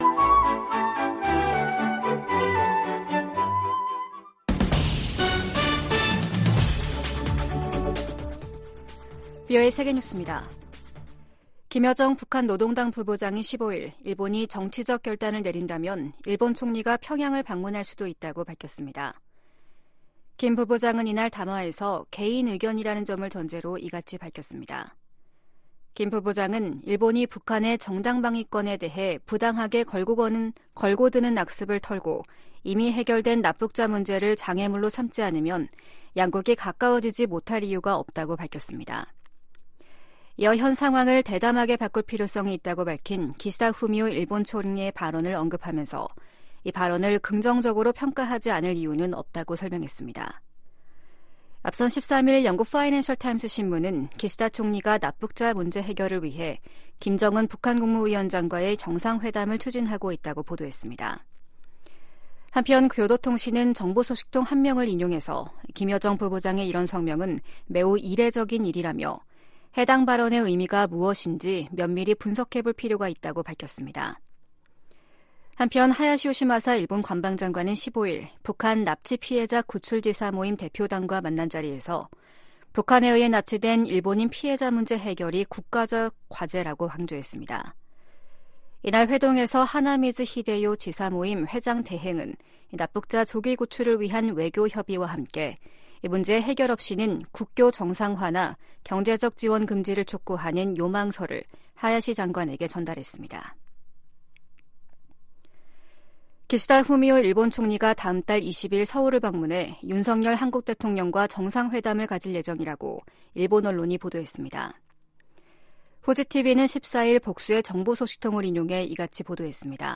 VOA 한국어 아침 뉴스 프로그램 '워싱턴 뉴스 광장' 2024년 2월 16일 방송입니다. 북한이 신형 지상 대 해상 미사일 시험발사를 실시했다고 관영 매체가 보도했습니다. 미 국무부 고위 당국자가 북한-러시아 밀착에 깊은 우려를 나타내며 다자 협력의 필요성을 강조했습니다.